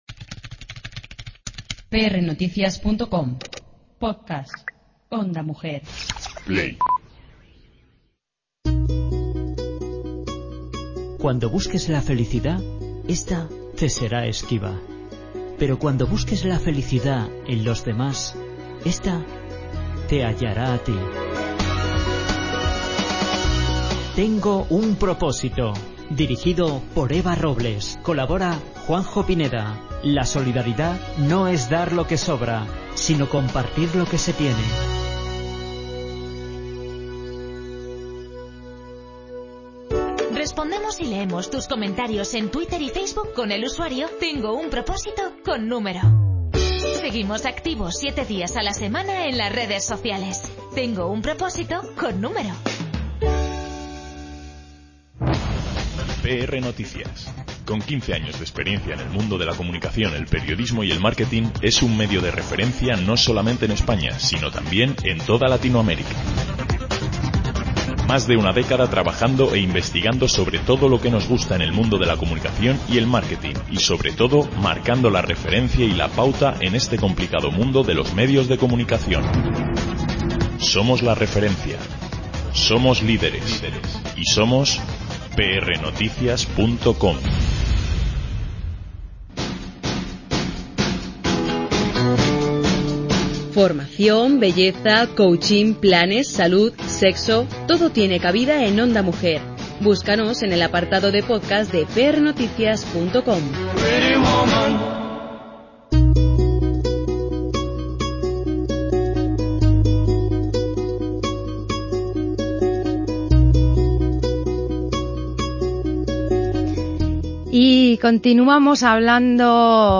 visita los estudios de Ondamujer para debatir
El miedo, la culpa, la violencia impiden que la mujer se vea capacitada a denunciar el maltrato al que se ve sometida. De hecho las cifras refrendan esta tesis que defiende nustra invitada, Cristina del Valle, en el debate que se plantea en el estudio de Onda Mujer.